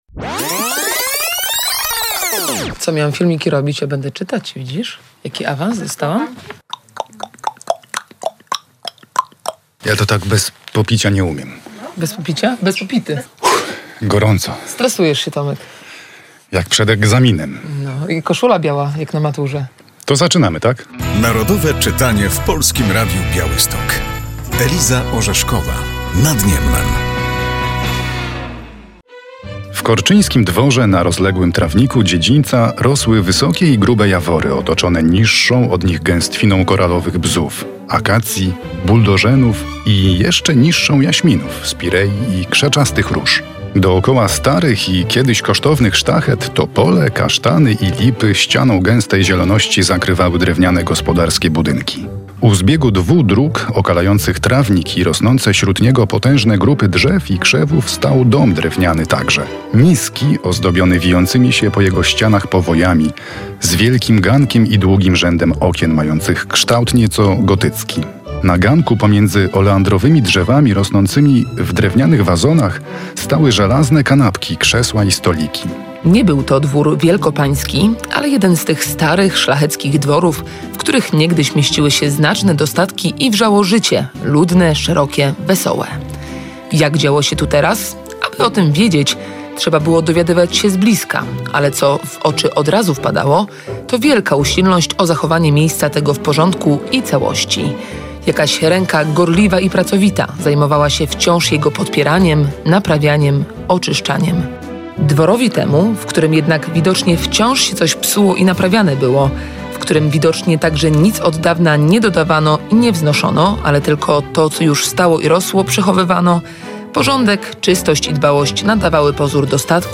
Pozytywistyczną powieść przybliża w tym roku ogólnopolska akcja Narodowe Czytanie. Włączają się do niej także dziennikarze Polskiego Radia Białystok.